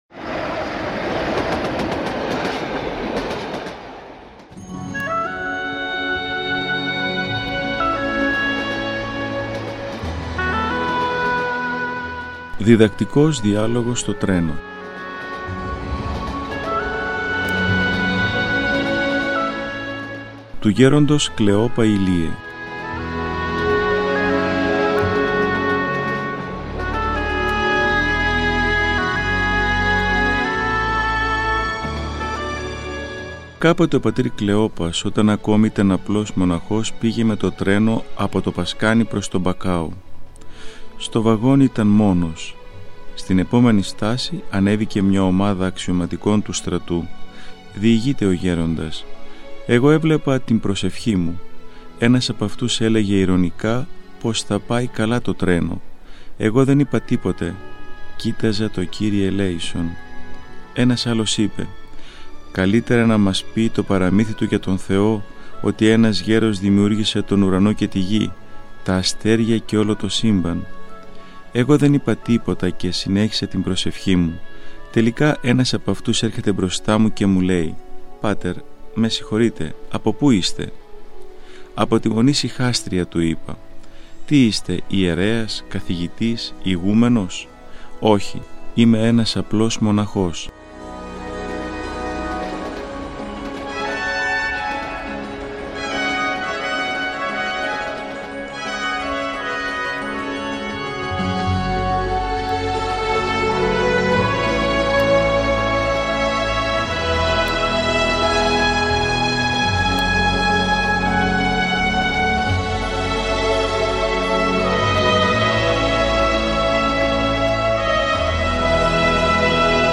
Ακούστε το επόμενο κείμενο, όπως αυτό «δημοσιεύθηκε» στο 171-ο τεύχος (Μαϊου – Ιουνίου του 2018) του ηχητικού περιοδικού μας, Ορθόδοξη Πορεία.